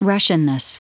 Prononciation du mot russianness en anglais (fichier audio)
russianness.wav